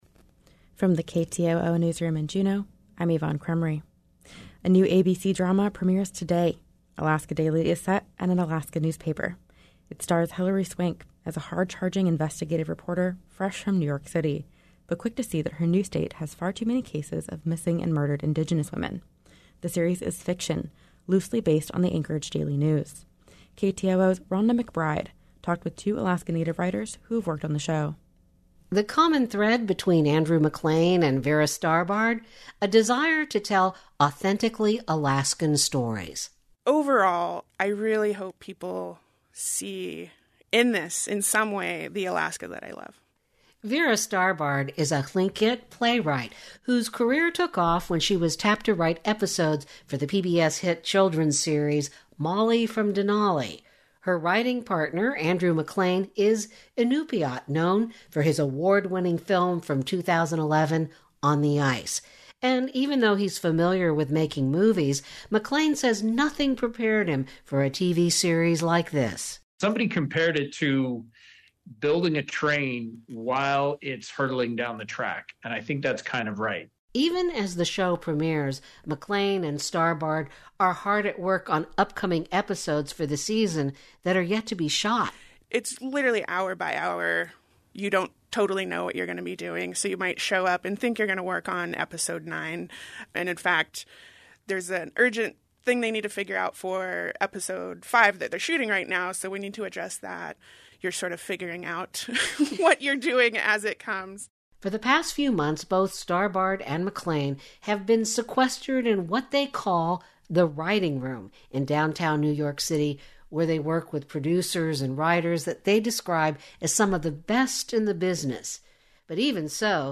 Newscast – Thursday, Oct. 6, 2022